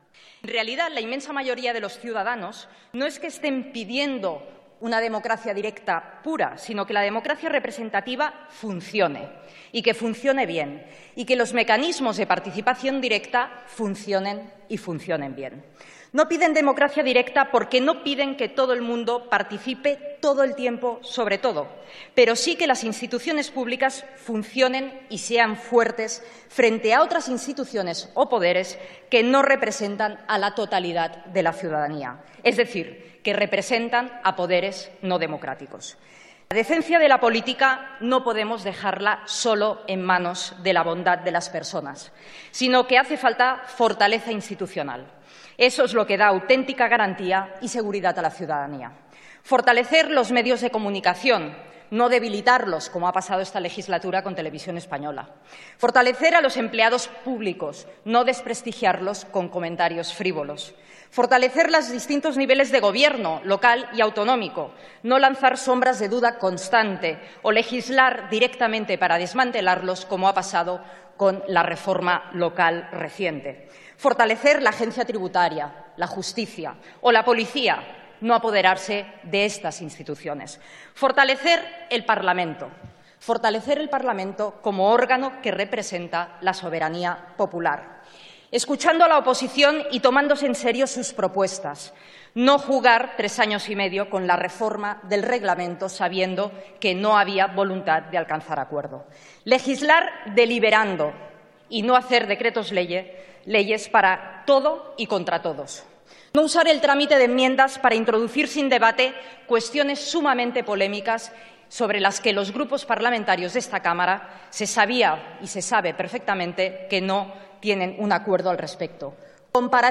Fragmento de la interevnción de Meritxell Batet defendiendo una proposición de ley para fomentar y facilitar las iniciativas legislativas populares